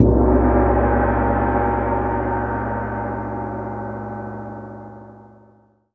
GONG 2.wav